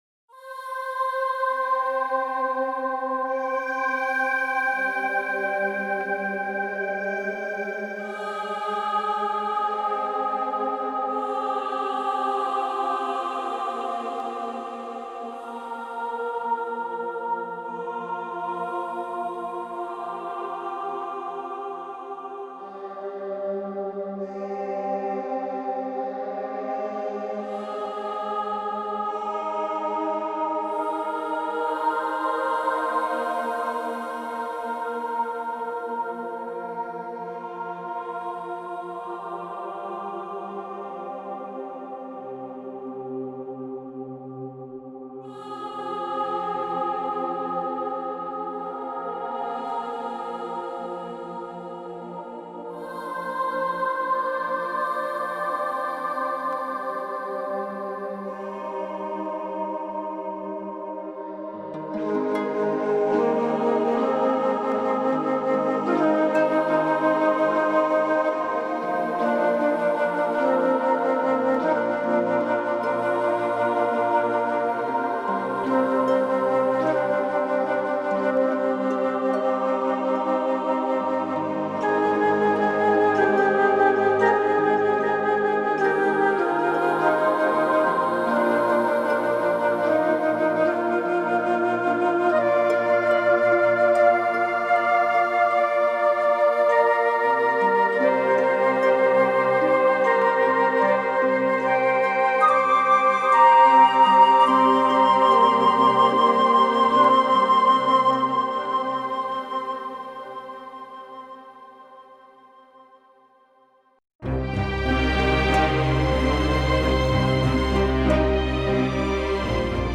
موسیقی متن